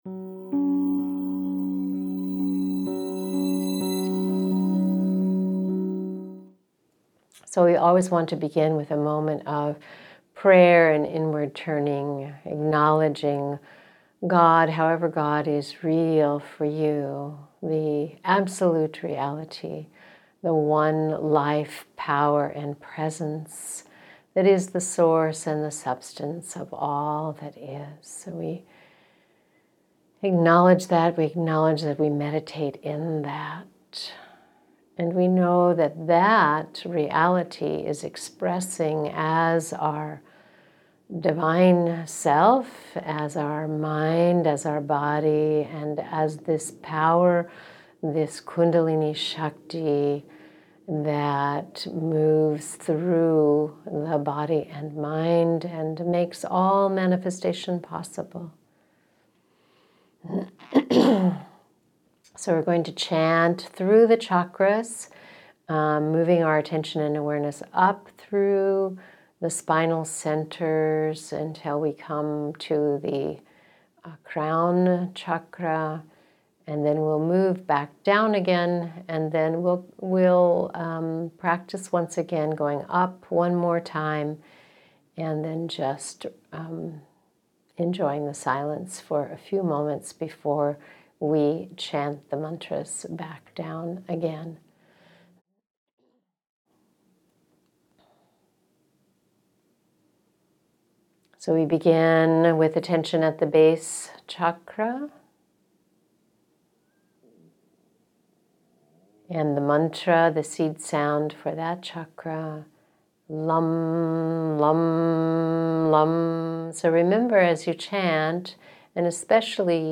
Bija-Mantra-Meditation.mp3